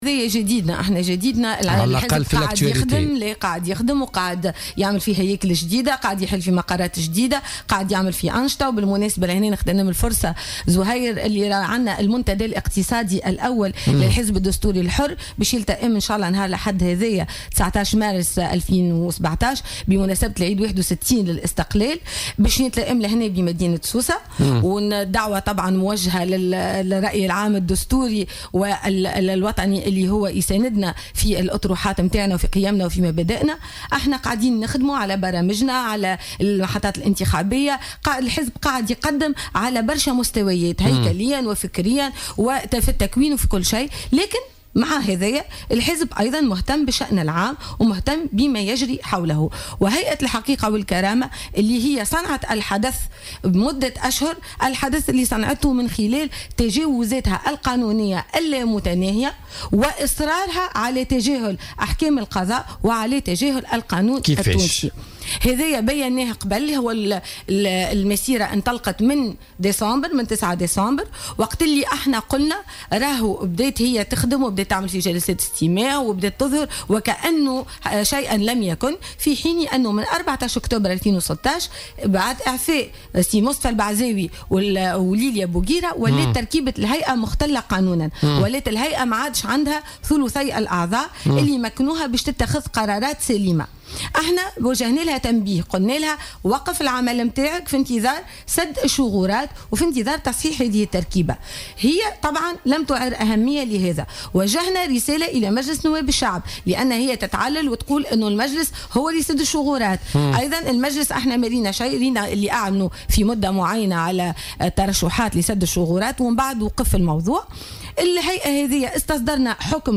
أكدت رئيسة الحزب الدستوري الحرّ عبير موسي ضيفة بولتيكا اليوم الجمعة 17 مارس 2017 أن التقاضي ضد هيئة الحقيقة والكرامة يأتي في إطار اهتمام حزبها بالشأن العام خاصة بعد التجاوزات الخطيرة التي تقوم بها هيئة الحقيقة والكرامة واصرارها على تجاهل أحكام القضاء والقانون التونسي.